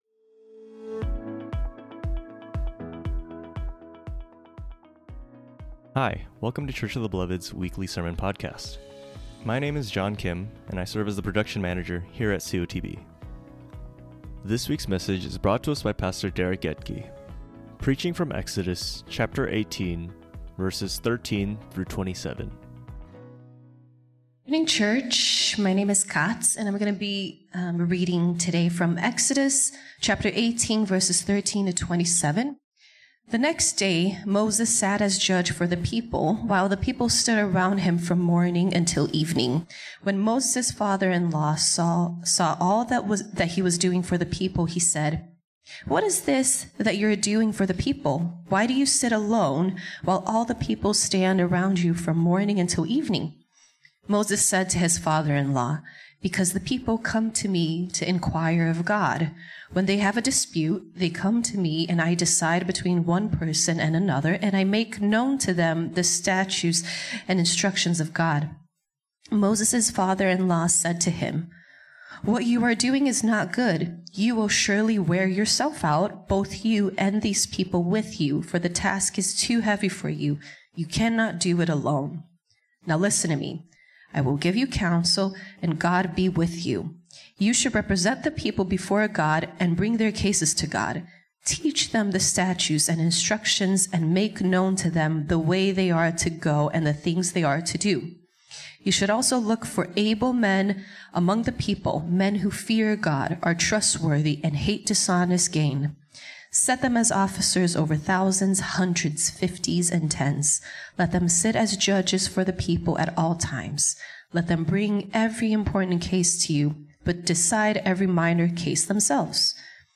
preaches from Exodus 18:13-27